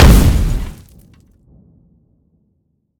small-explosion-1.ogg